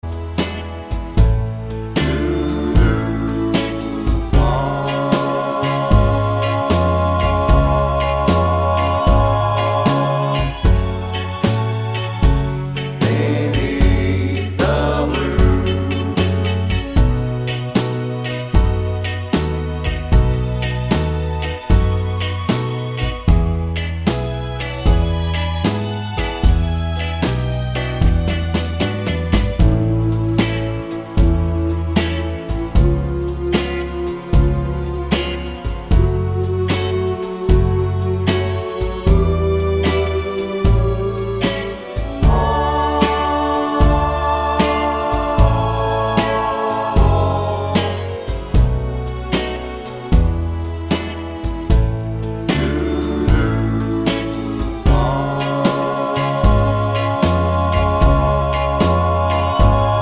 no Backing Vocals Soul / Motown 2:49 Buy £1.50